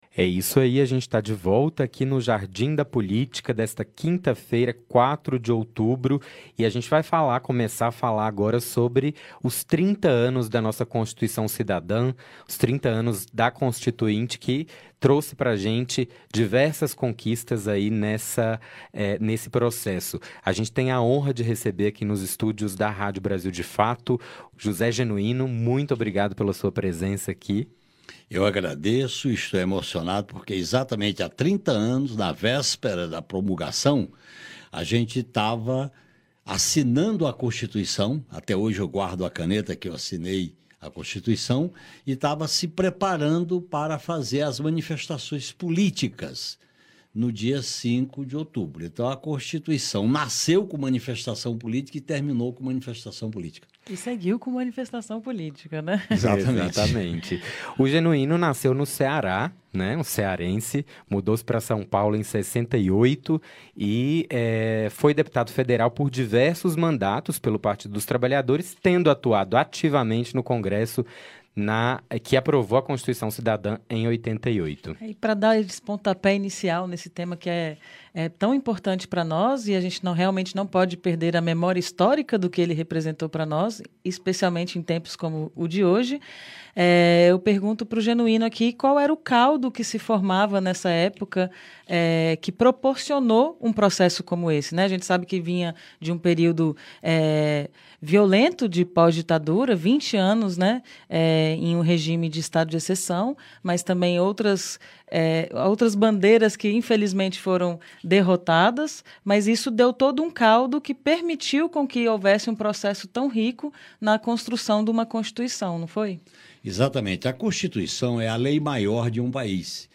Entrevista
Genoino participou do programa No Jardim da Política na semana de aniversário da Constituição